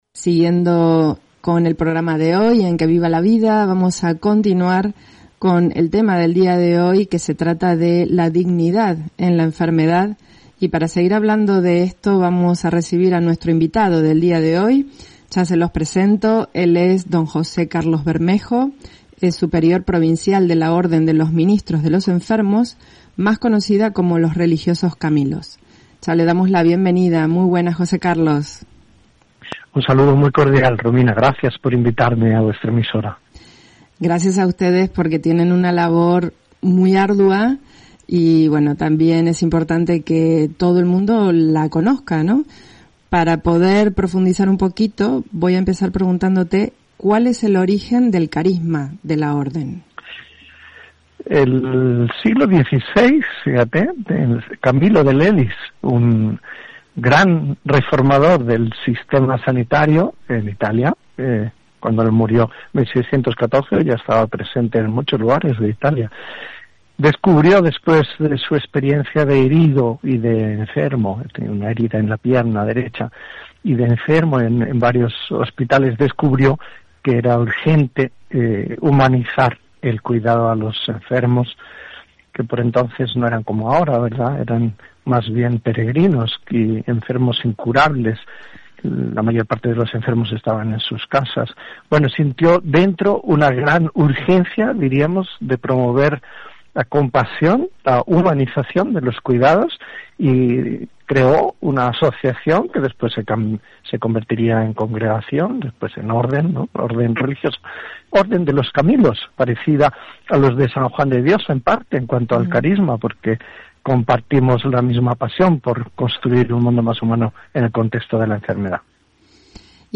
Entrevista en Radio Diocesana Canaria sobre la dignidad en la enfermedad
entrevista-en-Radio-Diocesana-Canaria.mp3